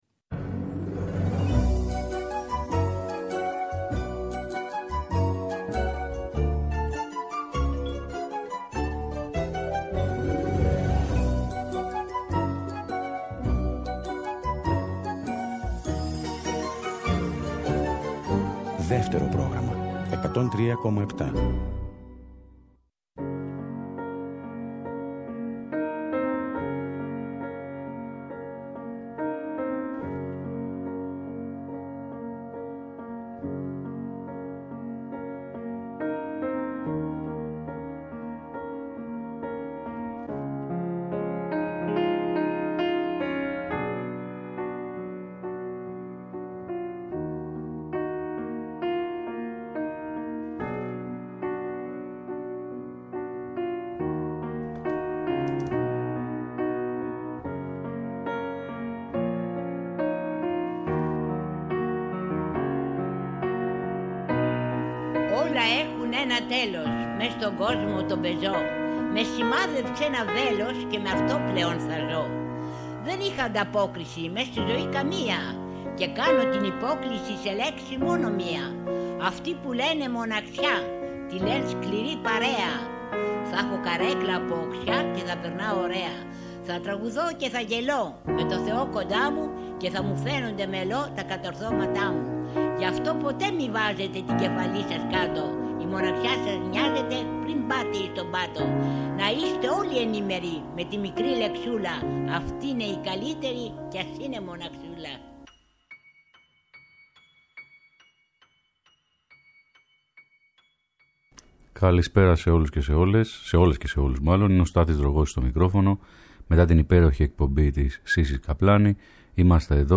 Tραγούδια ανοιξιάτικα ελληνικά και κλασσικά αυτό το Σάββατο 6 Απριλίου στην Αντέλμα στις 5 το απόγευμα ακριβώς!